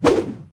footswing3.ogg